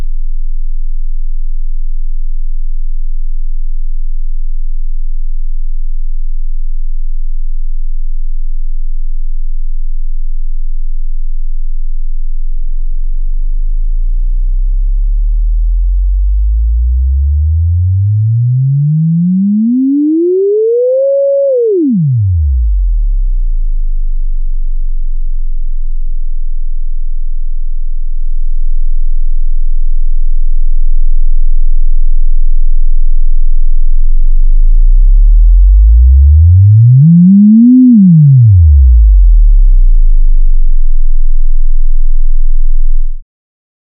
Но это как раз проблема излучателей - см. захваченную через лупбэк форму аудиосигнала - она идеальна. Сначала 20 Гц 50% громкости, затем небольшой свип и 100% громкости Вложения Output 1-2.wav Output 1-2.wav 12,1 MB · Просмотры: 151